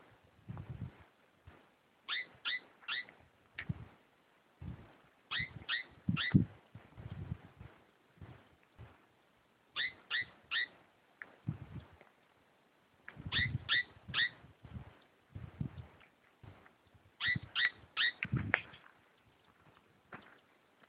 Rhinocrypta lanceolata
Un ejemplar observado, dio la voz de alarma y subia a un arbusto desde donde vocalizo y se lo pudo gravar.
Nome em Inglês: Crested Gallito
Localidade ou área protegida: Parque Provincial Chancaní
Certeza: Observado, Gravado Vocal
Gallito-Copeton.mp3